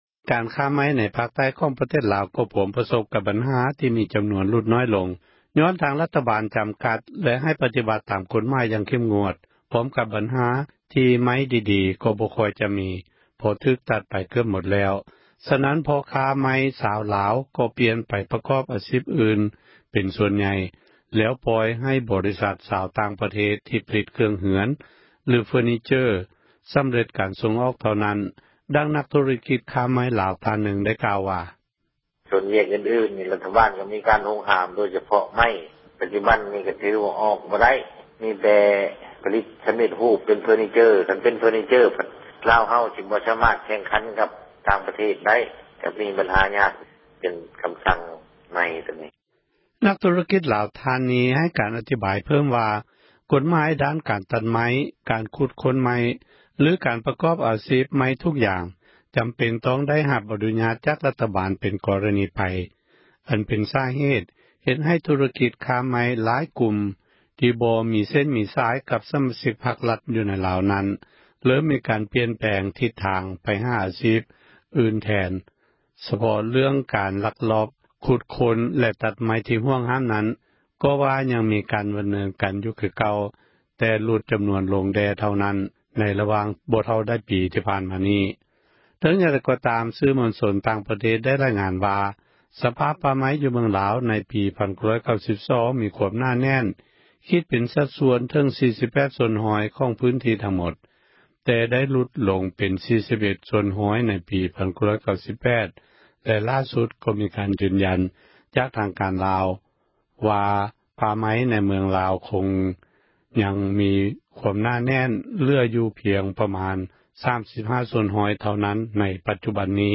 ກາຣຄ້າໄມ້ ໃນພາຄໃຕ້ ຂອງລາວ ພວມປະສົບ ກັບບັນຫາ ທີ່ມີຈຳນວນ ຫລຸດໜ້ອຍລົງ ຍ້ອນທາງ ຣັຖບາລຈຳກັດ ແລະ ໃຫ້ປະຕິບັຕ ຕາມກົດໝາຍ ຢ່າງເຂັ້ມງວດ ພ້ອມກັບບັນຫາ ທີ່ໄມ້ດີໆ ກໍບໍ່ຄ່ອຍຈະມີ ເພາະຖືກຕັດໄປ ເກືອບໝົດແລ້ວ ສະນັ້ນ ພໍ່ຄ້າໄມ້ ຊາວລາວ ກໍປ່ຽນໄປ ປະກອບອາຊີພ ອື່ນເປັນສ່ວນໃຫຍ່ ແລ້ວປ່ອຍໃຫ້ ບໍຣິສັທ ຕ່າງຊາຕ ທີ່ຜລິຕເຄື່ອງ ເຮືອນ ຫລື ເຟີຣ໌ນີເຈີຣ໌ (Furniture) ສຳຫລັບກາຣ ສົ່ງອອກເທົ່ານັ້ນ ດັ່ງນັກ ທຸຣະກິຈ ຄ້າໄມ້ລາວ ທ່ານໜຶ່ງ ໄດ້ກ່າວ: